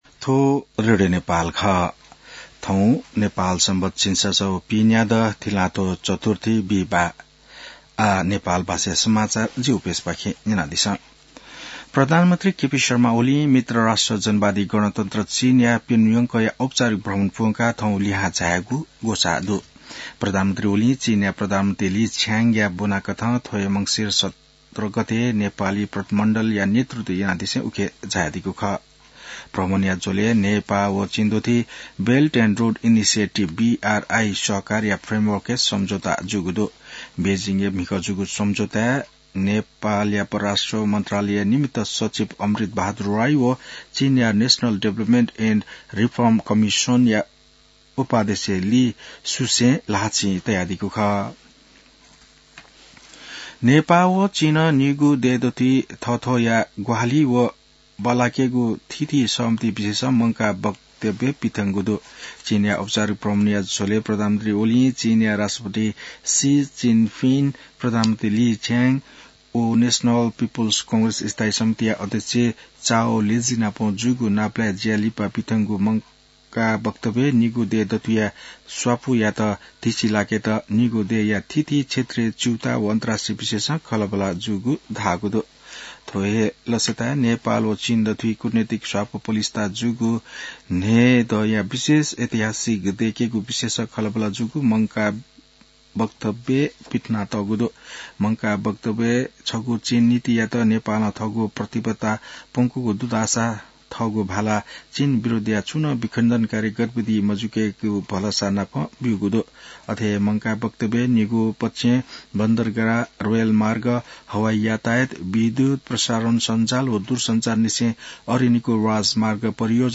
नेपाल भाषामा समाचार : २१ मंसिर , २०८१